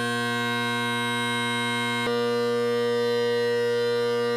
Tenors very smooth and powerful so would have to be paired with a different bass reed. Tenors are very bright.
this recording is of my Gellaitry’s with Kinnaird bass with both Crozier carbon tenors (first) and Kinnaird tenors (second), you can see why I stick with Kinnaird in the Gellaitry.
CrozierCarbonKinnairdtenorchange.wav